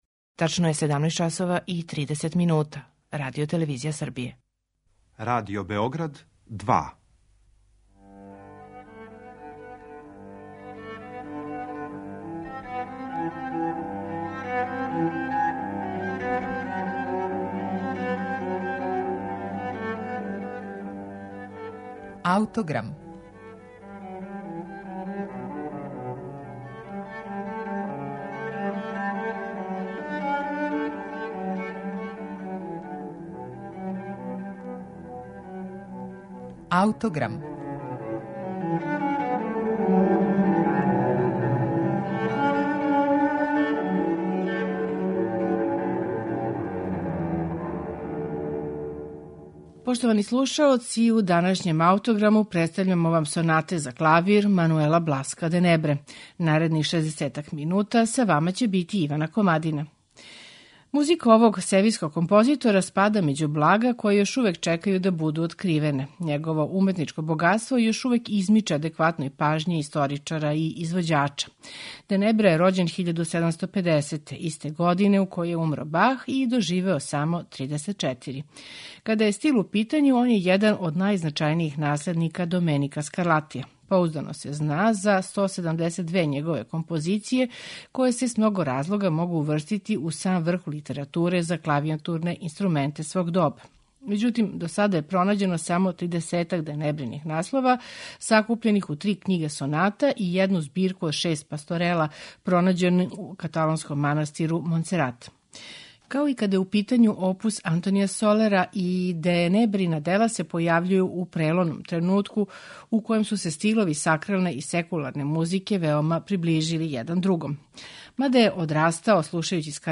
Мануел Бласкo де Небра: Сонате за чембало или клавир
У данашњем Аутограму представићемо де Небрине Сонате за чембало или клавир. Чућете их у интерпретацији пијанисте Хавијера Перианеса.